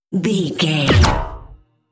Sci fi gun shot whoosh fast
Sound Effects
futuristic
intense
whoosh